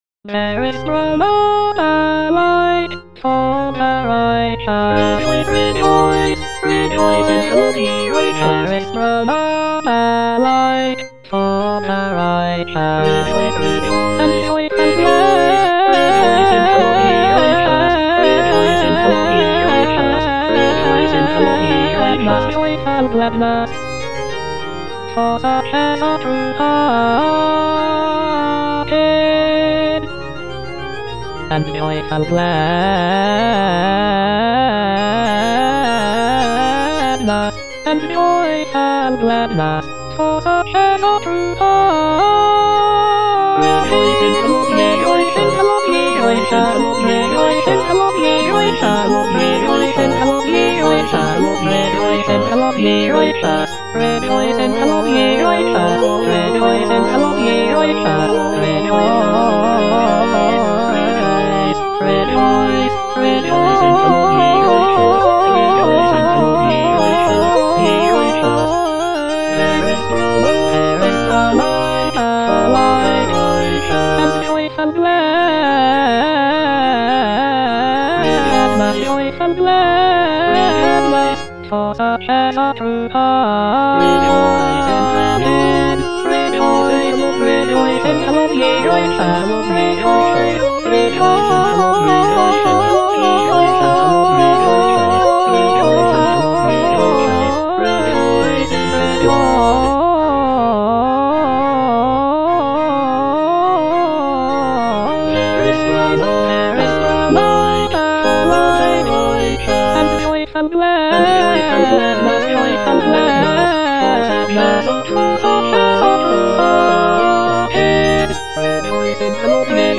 Choralplayer playing O come, let us sing unto the Lord - Chandos anthem no. 8 HWV253 (A = 415 Hz) by G.F. Händel based on the edition CPDL #09622
G.F. HÄNDEL - O COME, LET US SING UNTO THE LORD - CHANDOS ANTHEM NO.8 HWV253 (A = 415 Hz) There is sprung up a light - Alto (Emphasised voice and other voices) Ads stop: auto-stop
It is a joyful and celebratory piece, with uplifting melodies and intricate harmonies.
The use of a lower tuning of A=415 Hz gives the music a warmer and more resonant sound compared to the standard tuning of A=440 Hz.